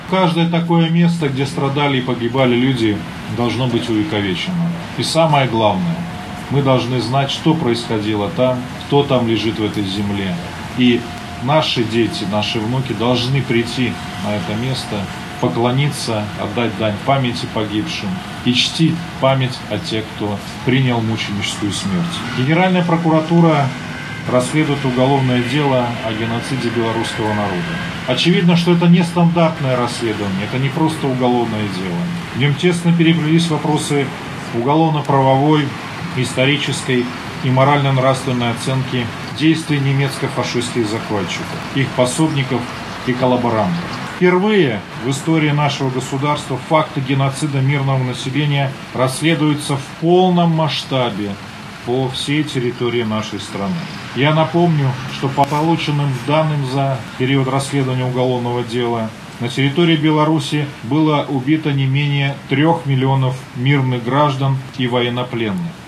Всего через эту пересыльную точку прошло не менее 20 тысяч человек, среди которых около 2 тысяч детей. почти 500 человек погибло. На месте  расположения лагеря состоялась церемония открытия  памятного знака.
Каждое такое место,  где страдали и гибли в войну люди – должно быть увековечено в нашей стране, отметил  Генеральный прокурор Андрей Швед.